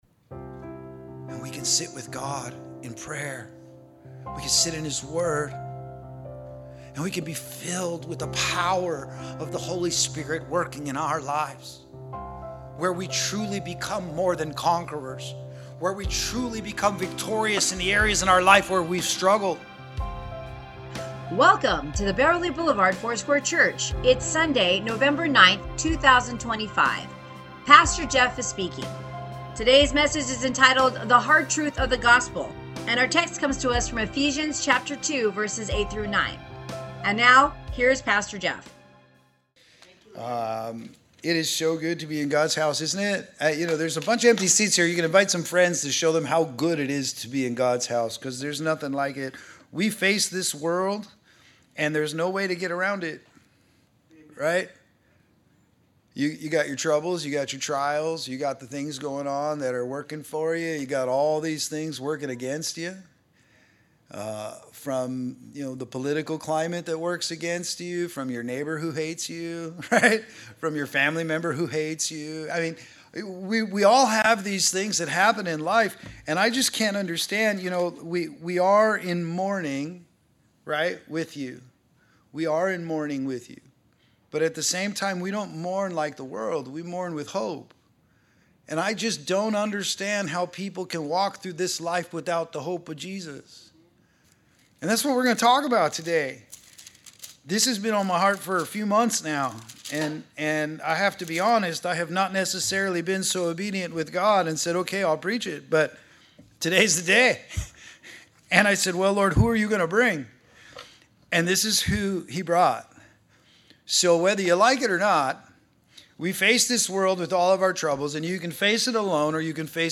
Sermons | Beverly Boulevard Foursquare Church